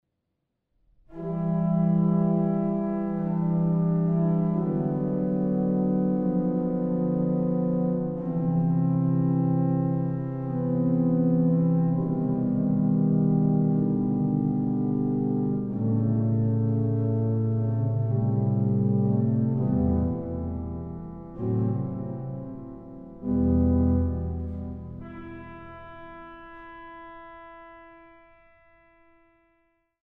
Kern-Orgel in der Frauenkirche Dresden
Orgel